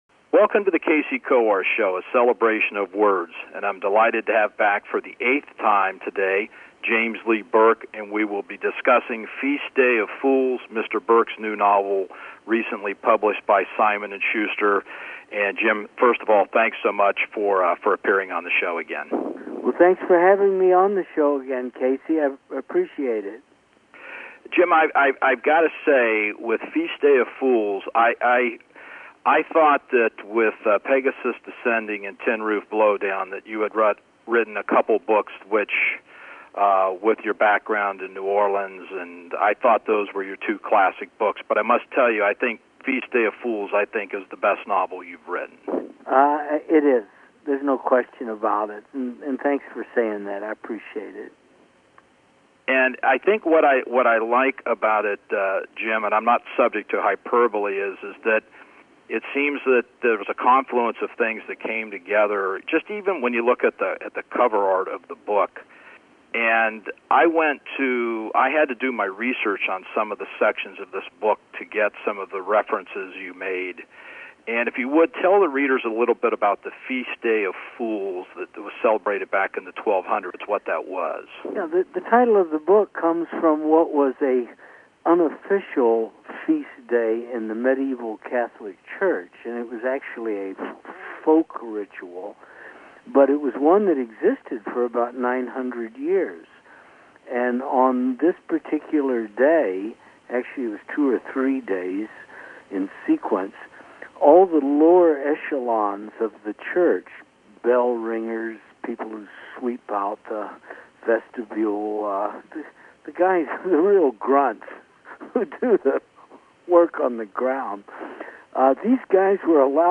New Interview- James Lee Burke (FEAST DAY OF FOOLS)